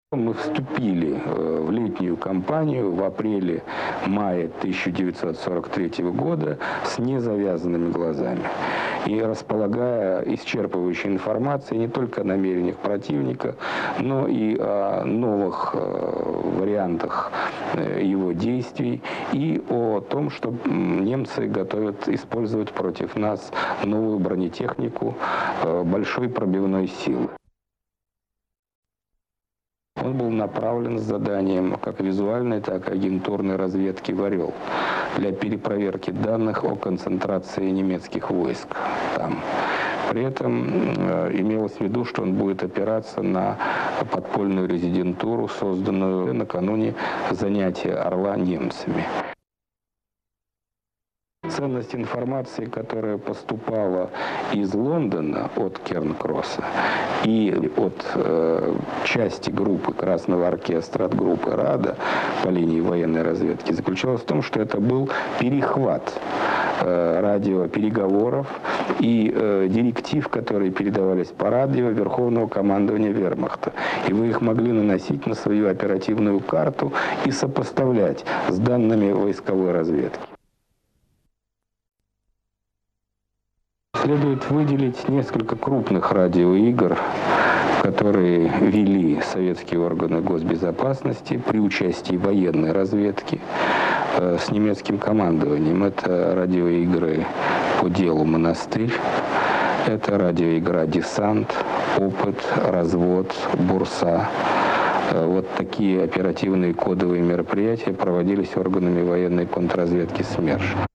Генерал-лейтенант НКВД Павел Судоплатов рассказывает о роли советской разведки в Курской битве (Архивная запись).